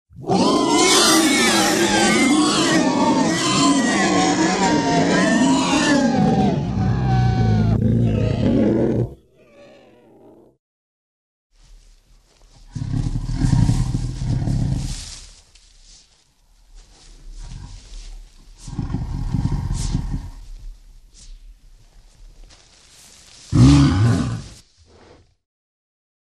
Звук жуткого визга